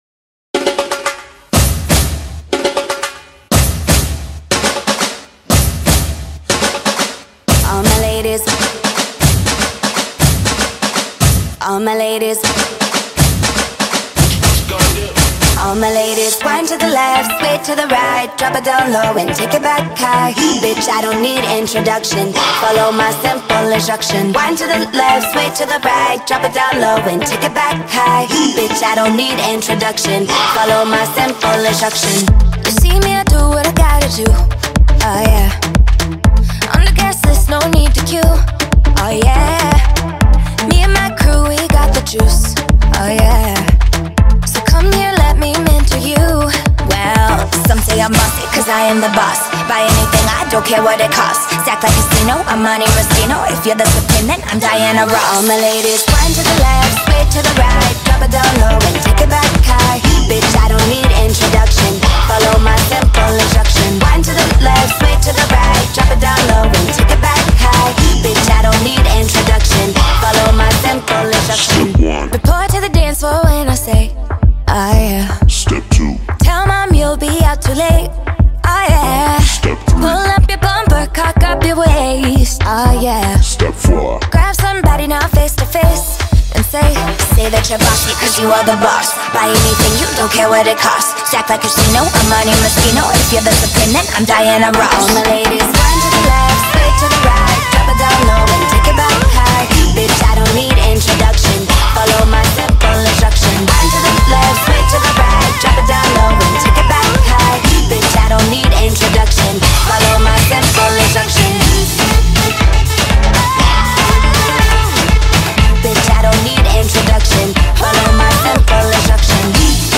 BPM121